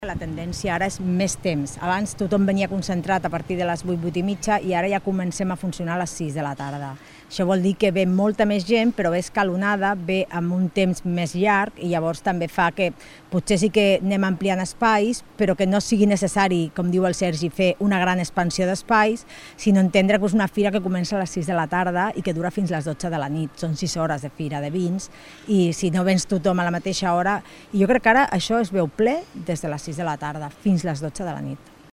Rosa Cadenas, regidora de Promoció Econòmica